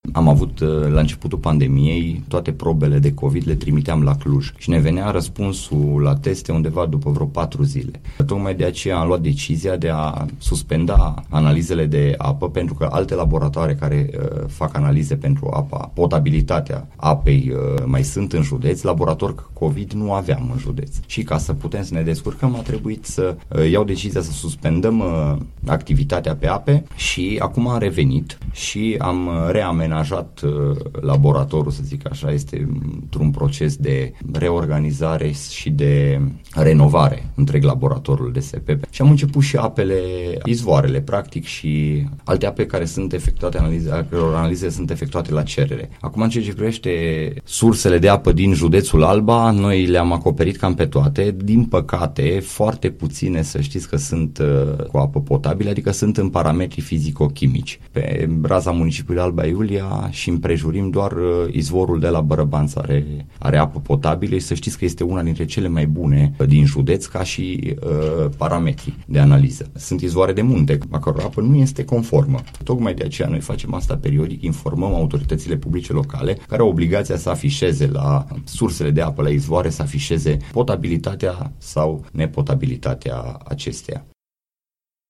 Este declarația făcută la Unirea FM de directorul DSP Alba, Alexandru Sinea.